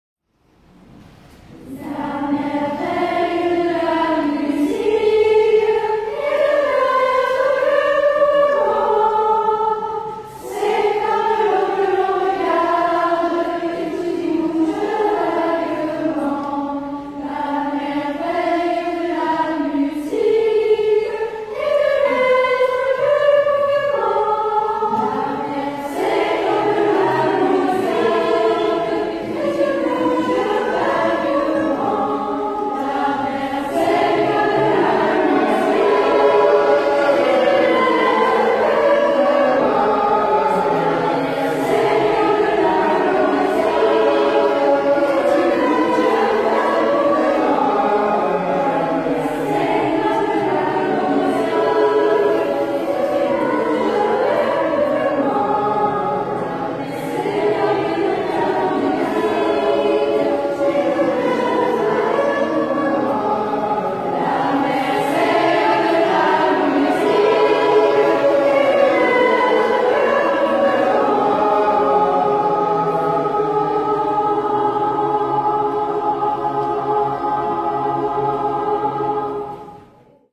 Canon à trois voix
Version originale